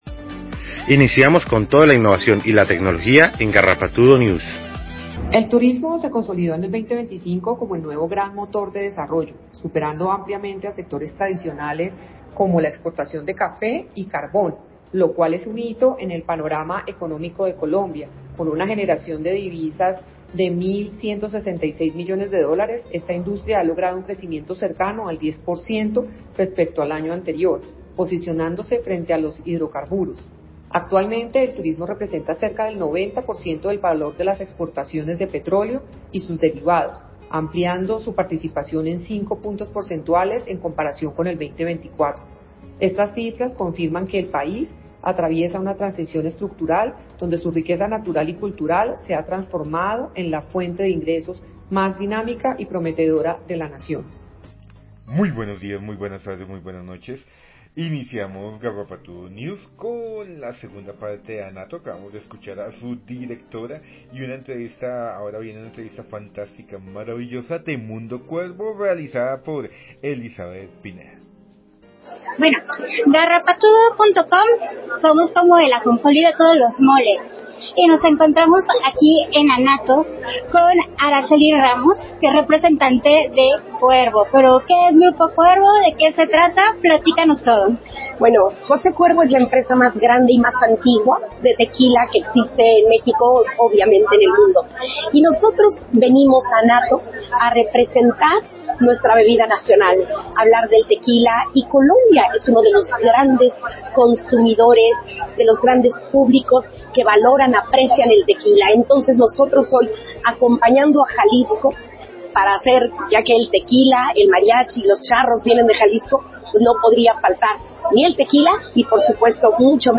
El Mundo en la Vitrina de ANATO Continuamos con la segunda parte de nuestro cubrimiento especial en la Gran Vitrina de ANATO.